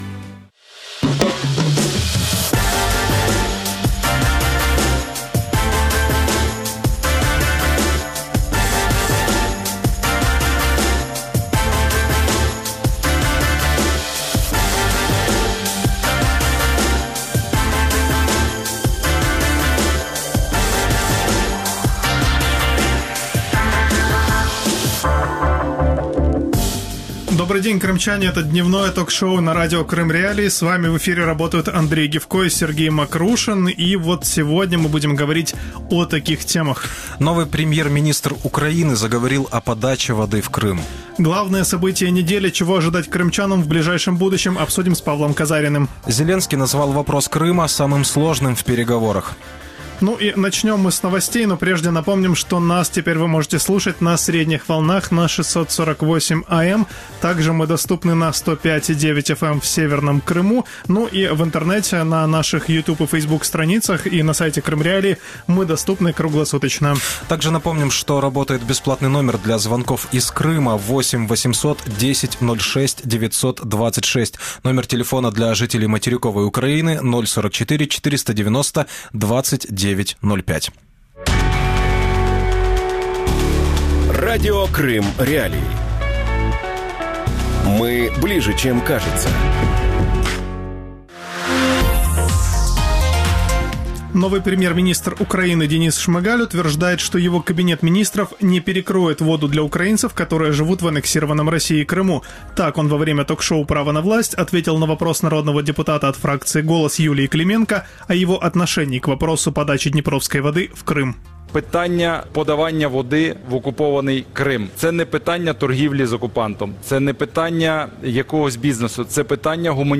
Телеканал, вода… Чего ждать Крыму от Кабмина | Дневное ток-шоу